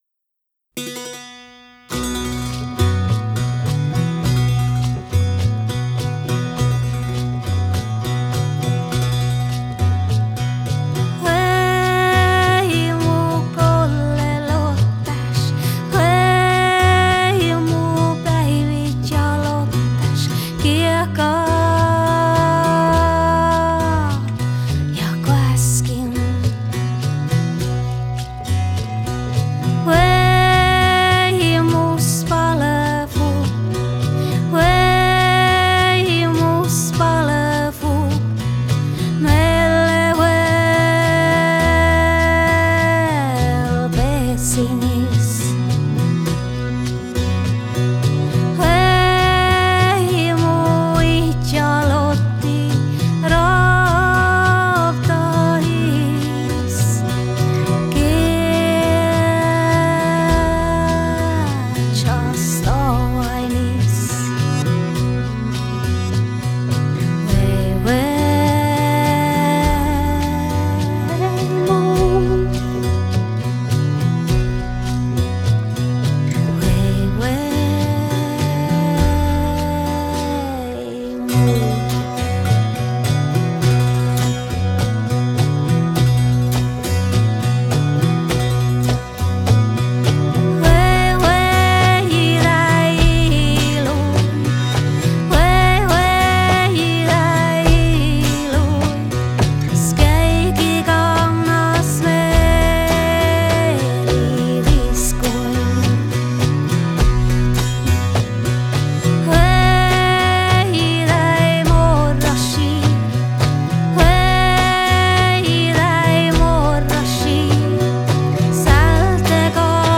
Style: Saami folk, Joik
Stereo